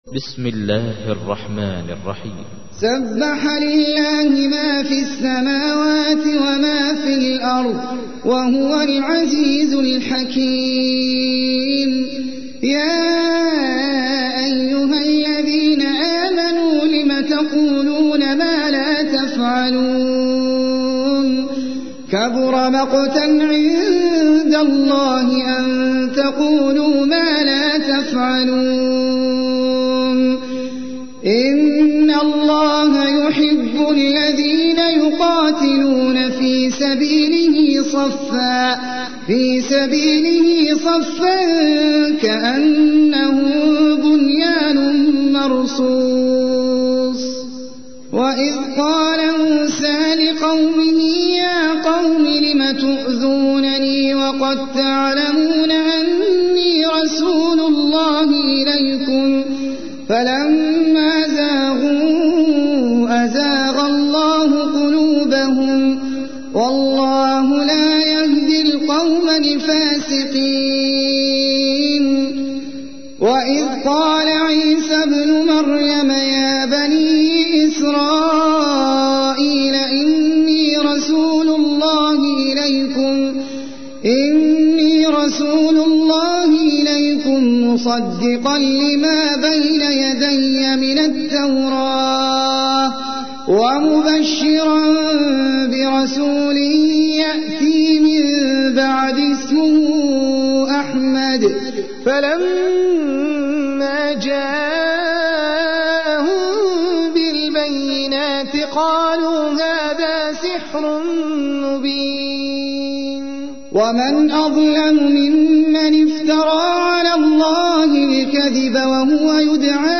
تحميل : 61. سورة الصف / القارئ احمد العجمي / القرآن الكريم / موقع يا حسين